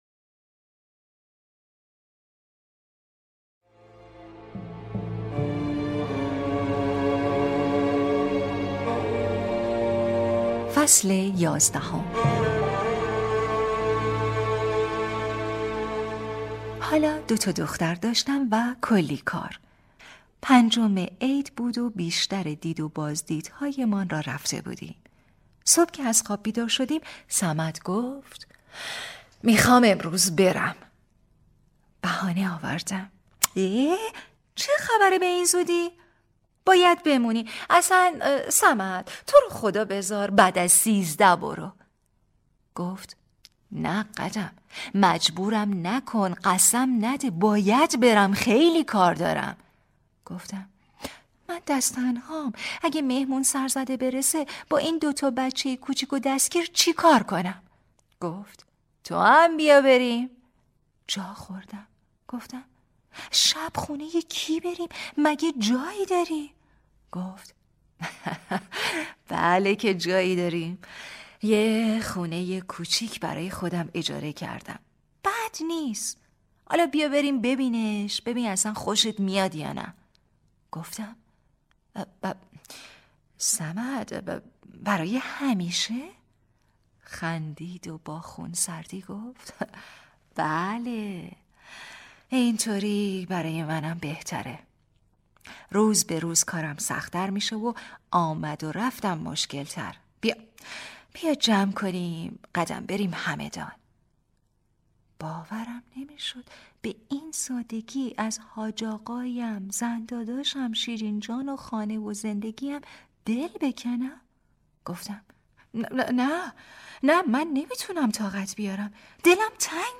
کتاب صوتی | دختر شینا (09)